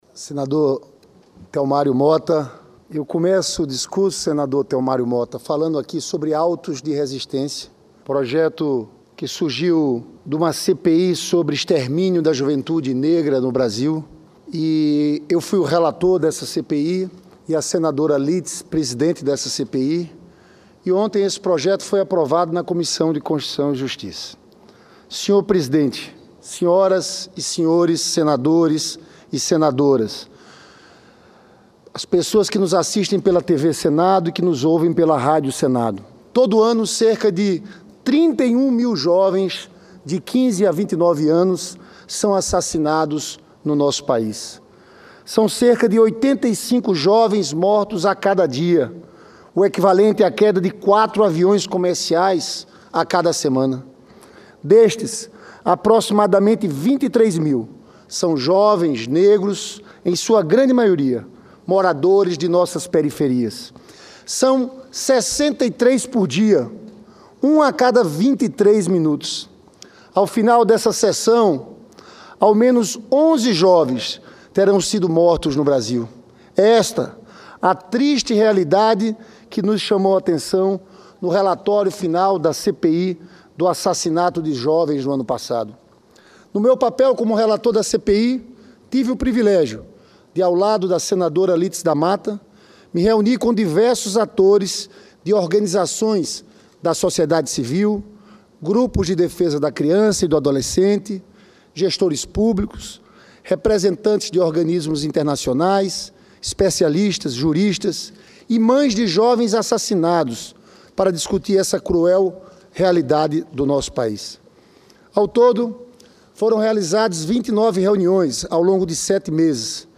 Plenário 2017
Discursos